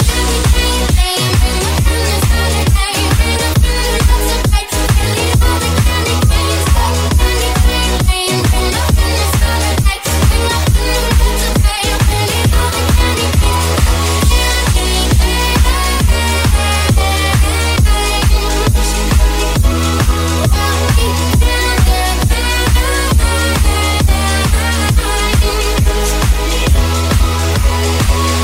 Genere: edm,deep,bounce,house,slap,cover,remix hit